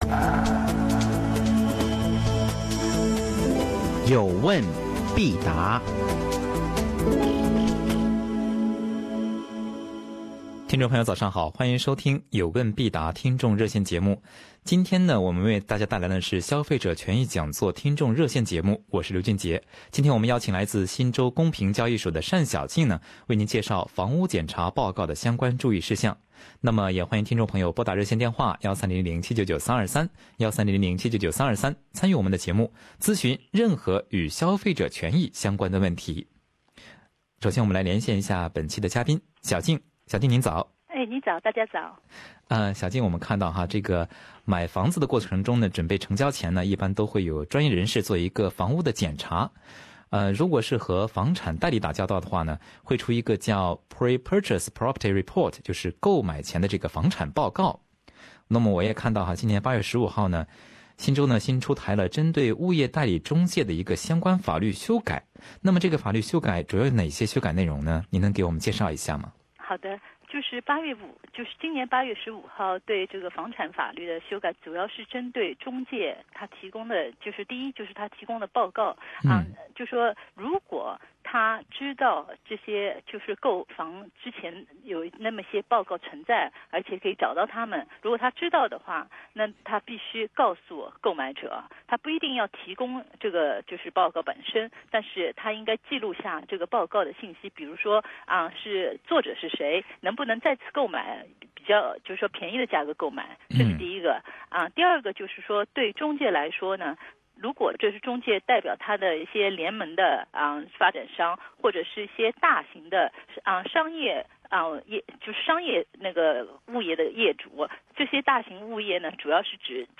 听众热线节目